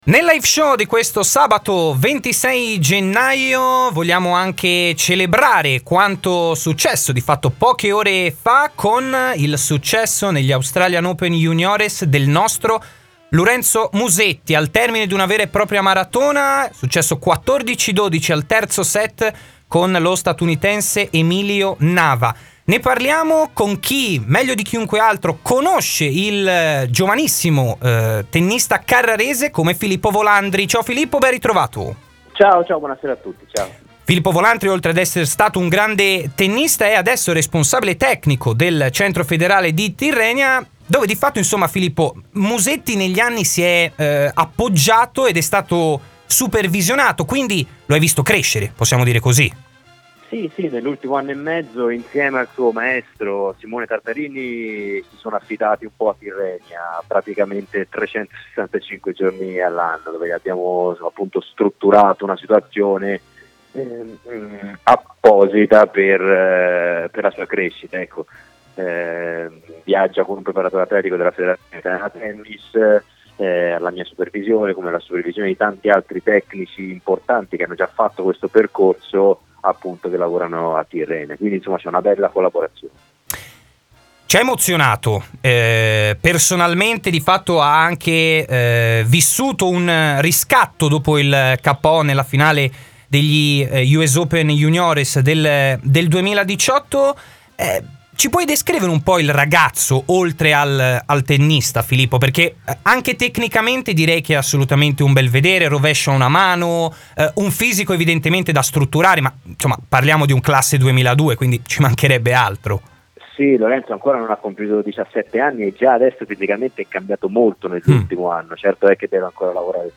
Filippo Volandri, ex tennista e responsabile Centro Federale di Tirrenia, sulla vittoria di Lorenzo Musetti negli Australian Open juniores. In studio